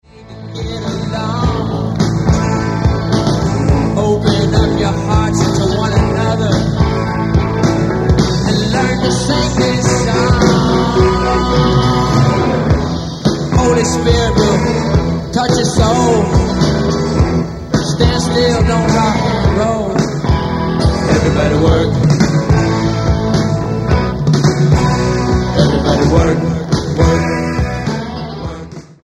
STYLE: Jesus Music